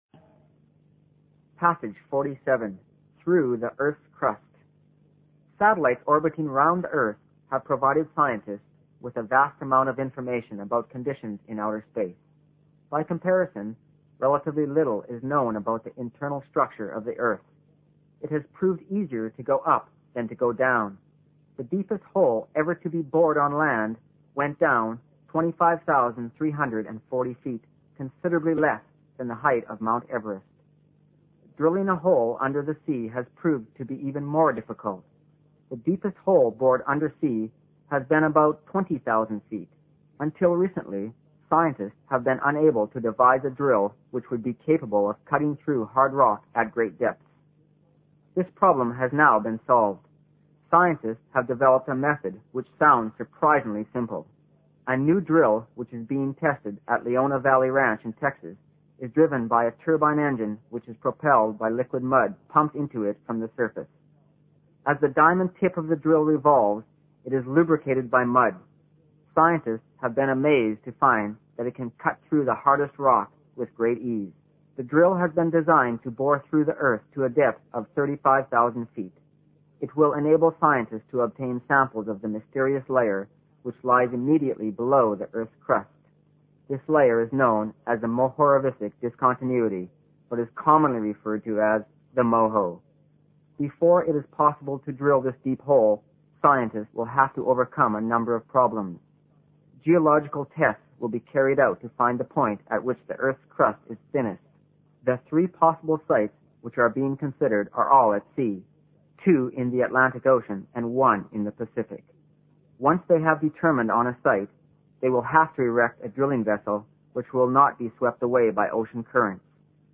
新概念英语85年上外美音版第三册 第47课 听力文件下载—在线英语听力室